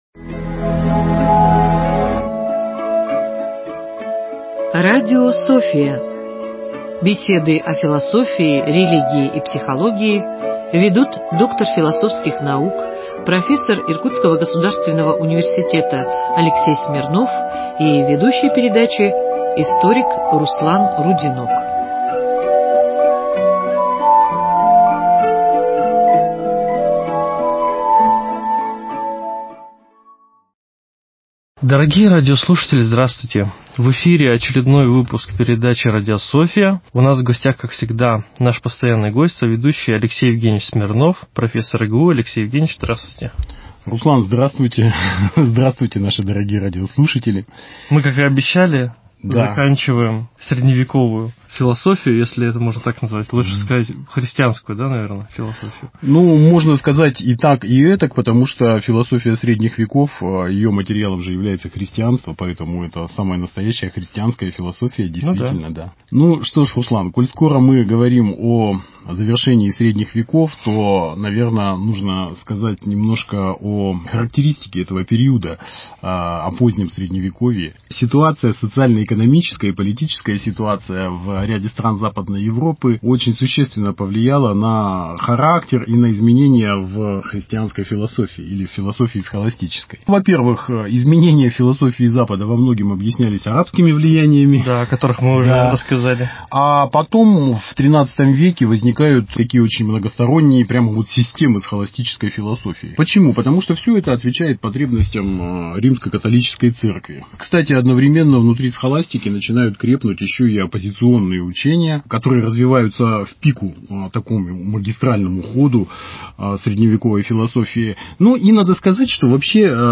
Беседы о философии, религии и психологии ведут доктор философских наук